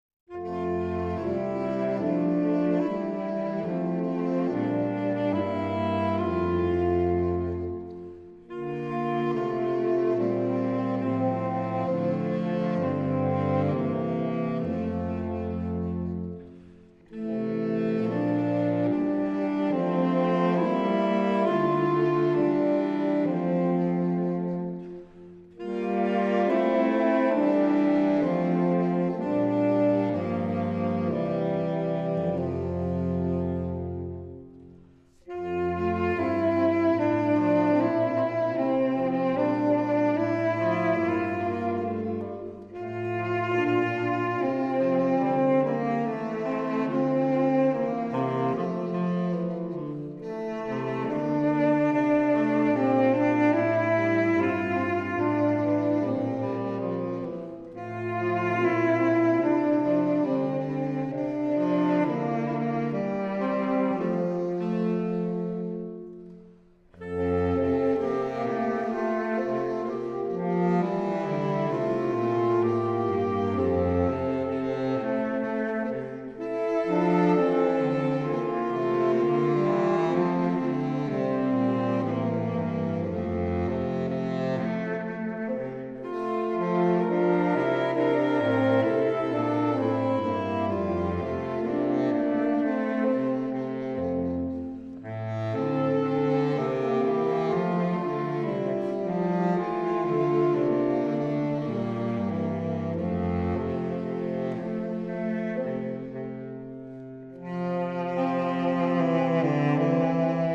saxophones